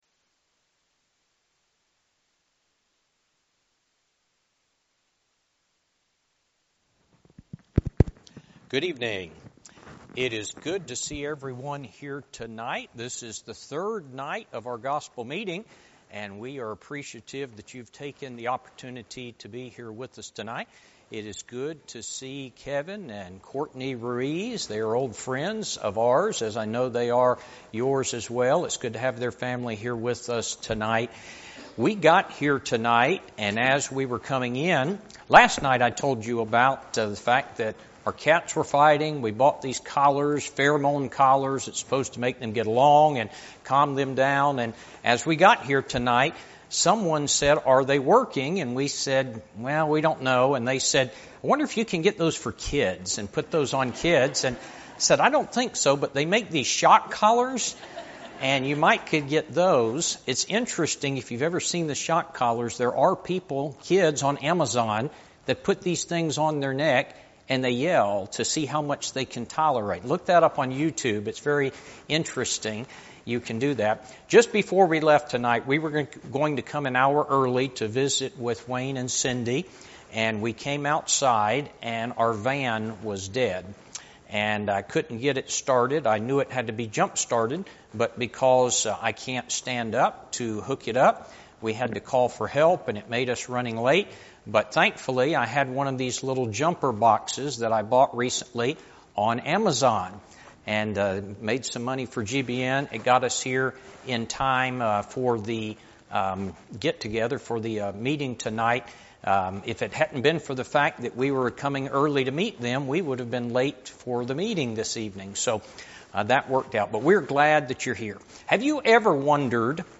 Spring Meeting Service Type: Gospel Meeting Download Files Notes « Where Do We Go When We Die?